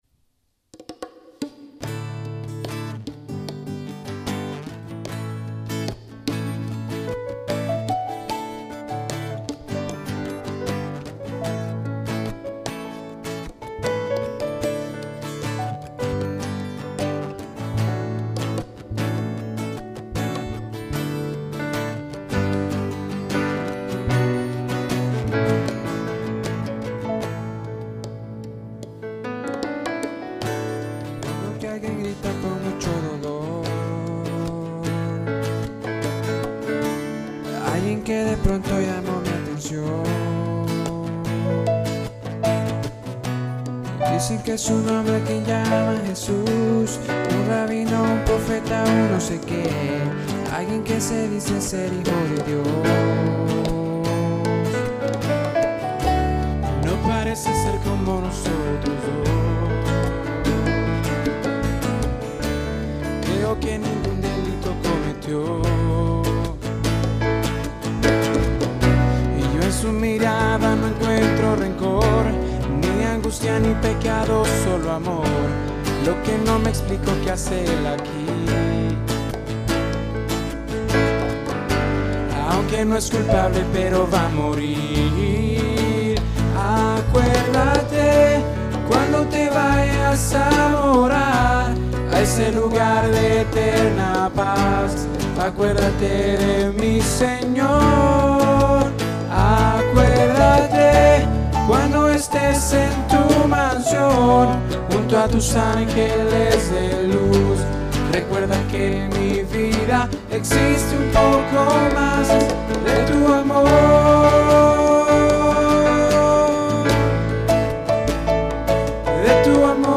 It hasn't been edited at all. I recorded it using two different sound systems so we would have enouch inputs, then I plugged them both into the two H4 inputs.
Because everyghing was amplified so people could hear themselves it was basically impossible to accurately set levels. I couldn't tell what was coming through the headphones and what was bleeding around.
bass